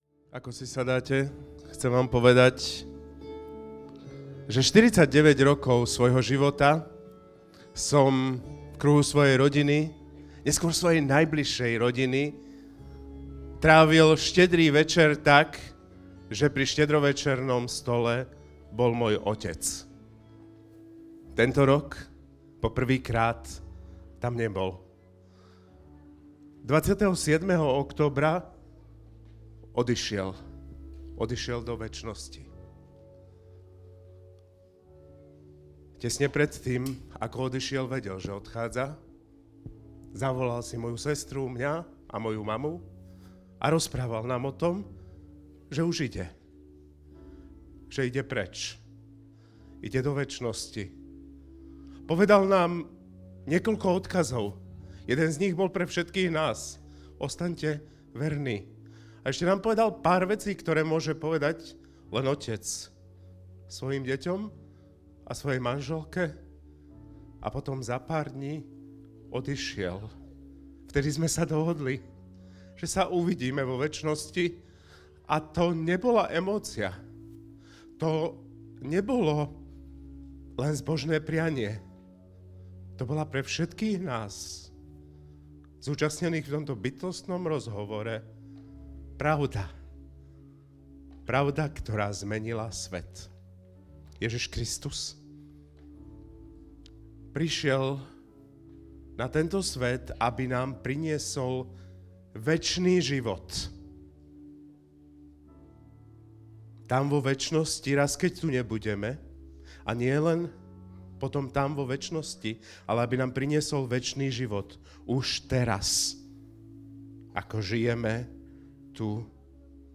Pozývame Vás vypočuť si Vianočné posolstvo o skutočnom zázraku.